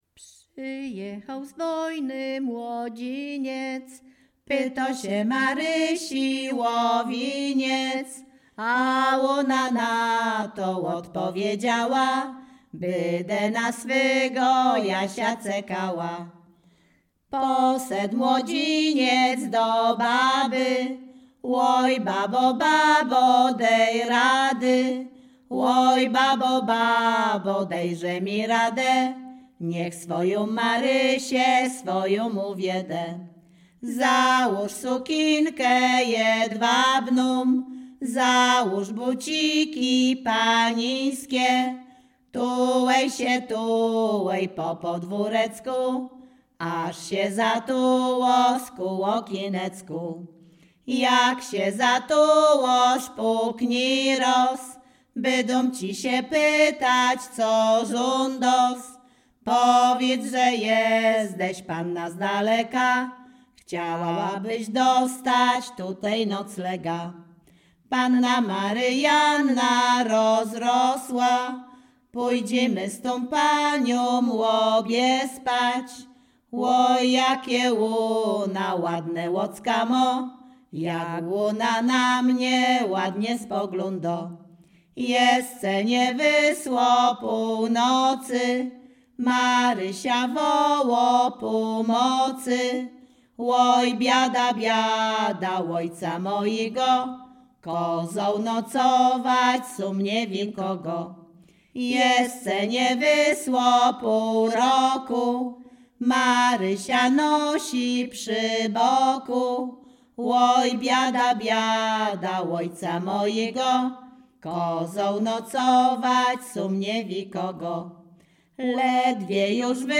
liryczne miłosne